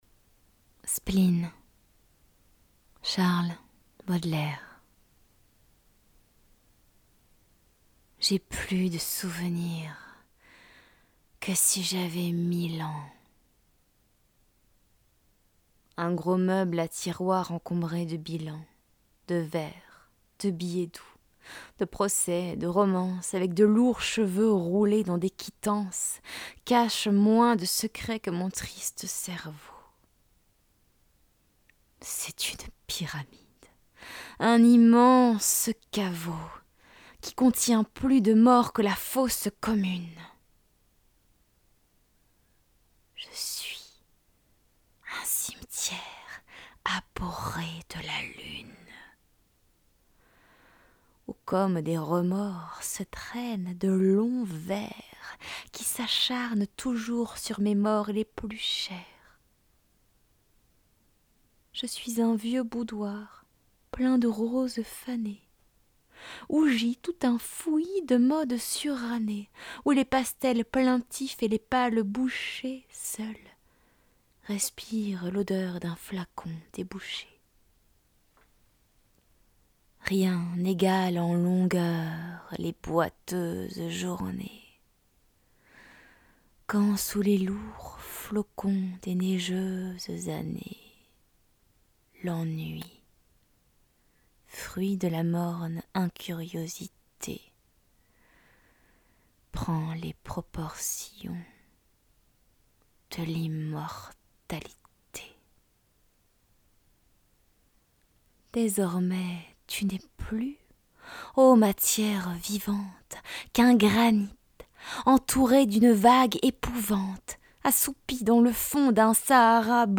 Chuchotements